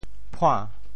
潮州拼音“puan2”的详细信息
潮州府城POJ phuáⁿ